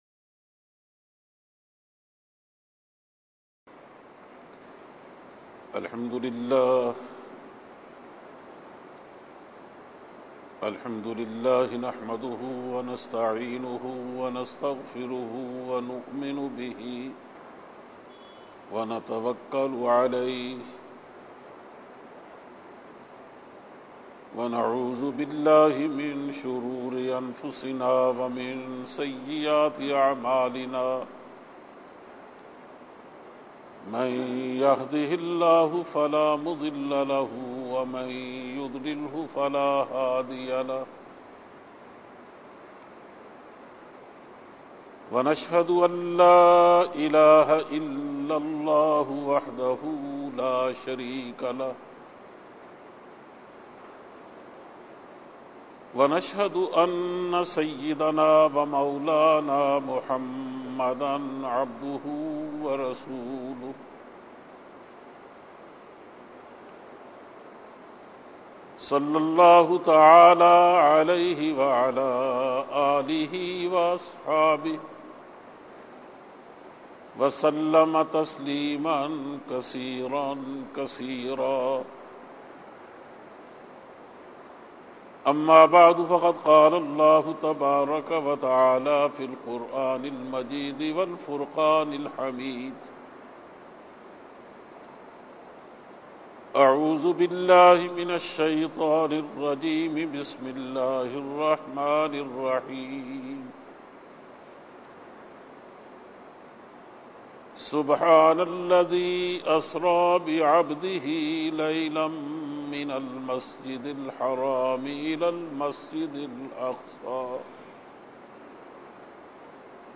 Khutba-e-Juma